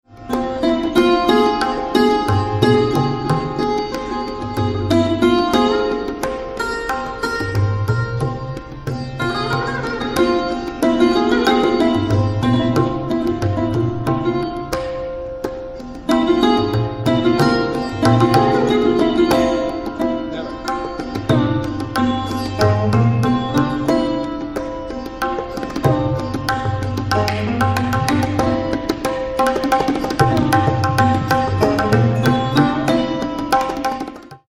A captivating shadav raga created by my own sitar guru-ji Pandit Shivnath Mishra, resembling ‘Charukeshi no Pa’.
sarod
filmed at a 2024 Vermont concert
tabla